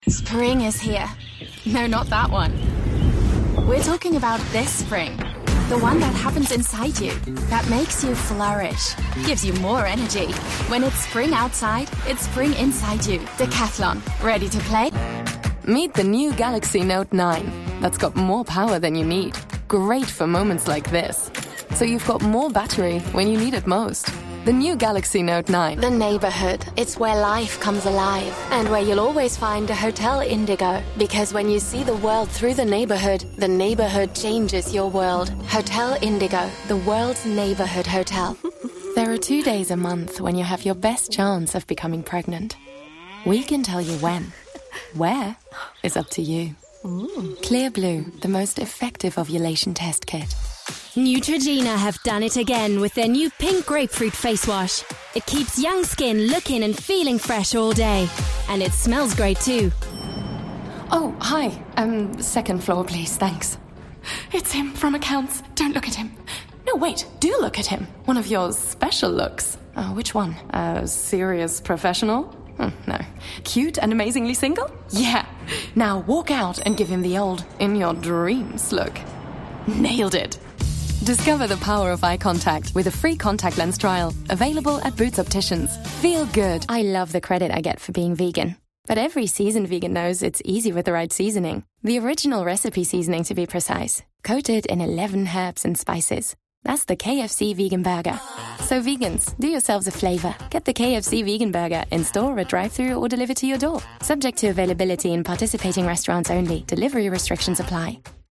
Demo comercial
Mi voz es identificable, contemporánea y juvenil con un sonido cálido y texturizado.
Desde mi estudio hogareño con calidad de transmisión en Londres, he trabajado con cientos de clientes de todo el mundo, entregando un trabajo de primera calidad en alemán, inglés y francés.